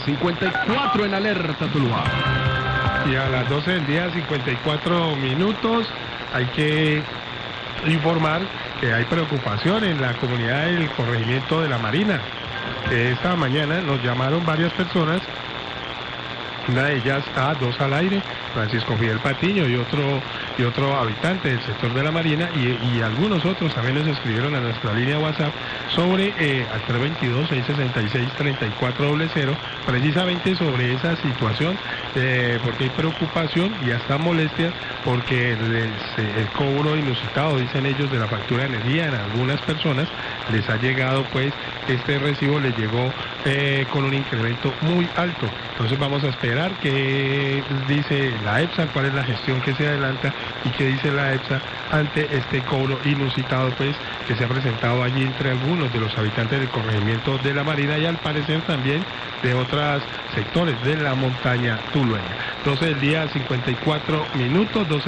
Radio
Oyente del corregimiento de La Marina solicita a través del noticiero que funcionario de Cetsa le explique por qué está llegando tan caro el recibo, se queja de incremento injustificado.